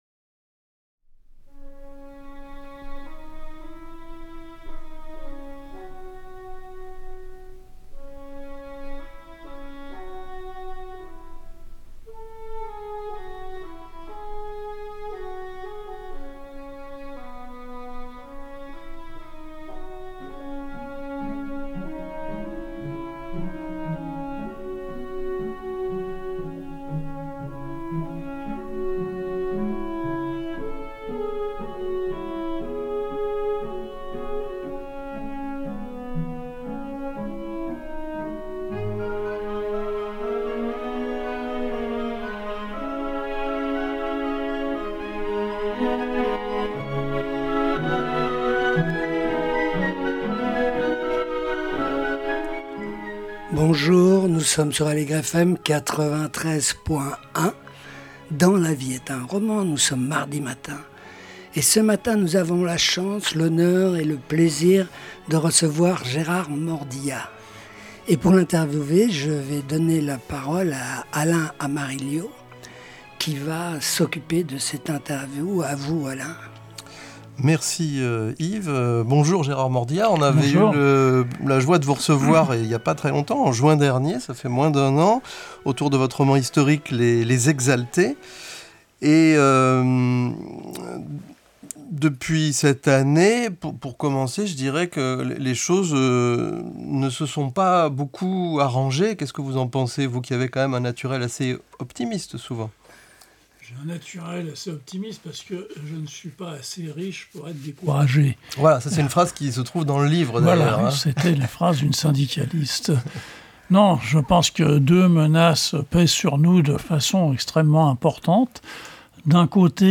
Nous avons reçu Gérard Mordillat pour son dernier roman Les Vivants et les Morts, vingt ans plus tard paru chez Calmann-Lévy.